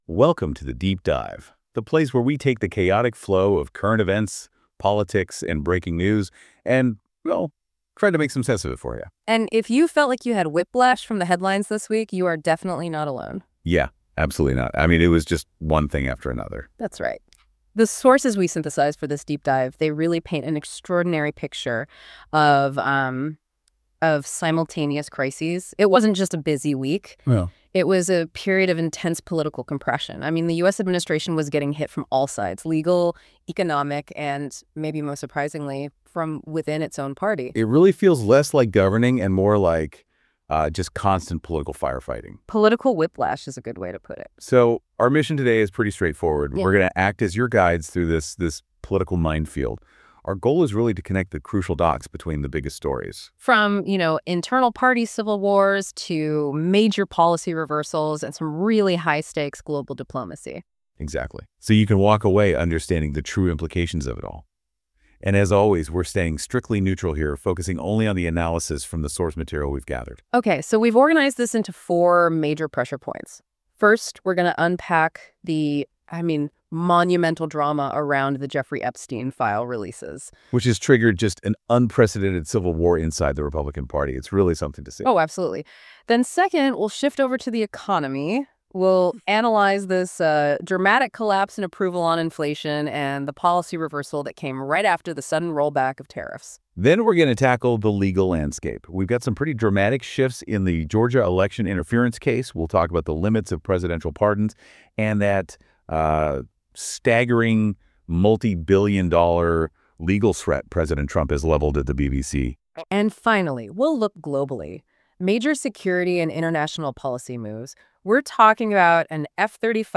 A Narrative Style That Feels Like Storytelling, Not Shouting Daily Story Brief borrows more from narrative audio and documentary storytelling than from traditional shouty talk radio. The tone is calm, structured, and focused.